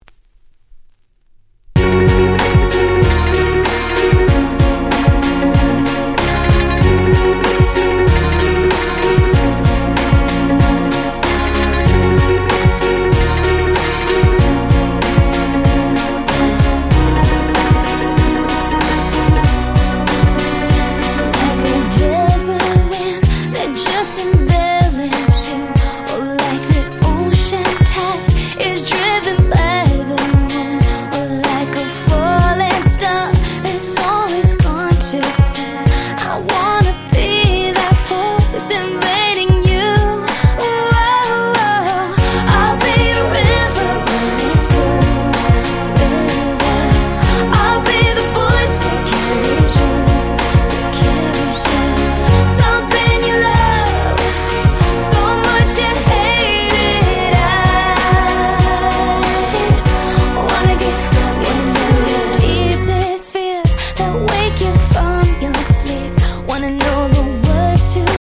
08' Nice EU R&B !!
キャッチー系